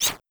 Whoosh & Slash
Slash4.wav